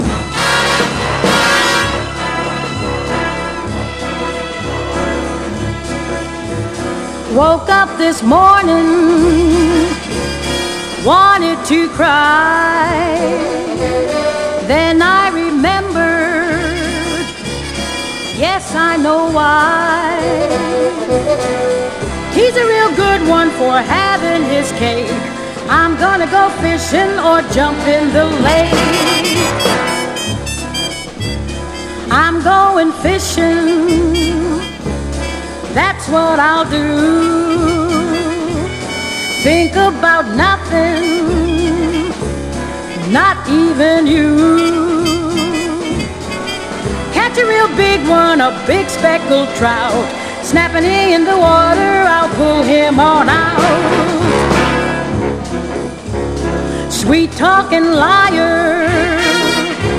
JAZZ / JAZZ VOCAL / JIVE / OLDIES / BLUES / RHYTHM & BLUES
ジャカジャカ・ギターが響く